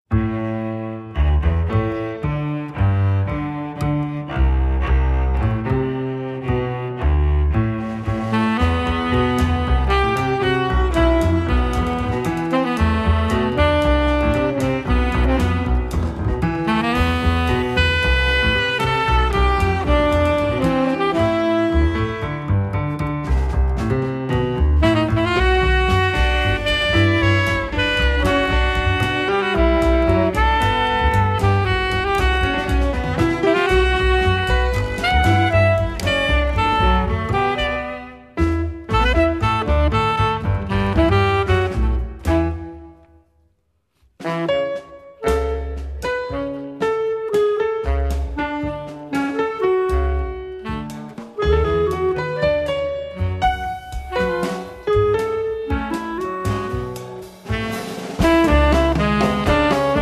Tenor and soprano saxophones
Piano
double bass
drums